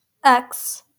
Its name in English is ex (pronounced /ˈɛks/ (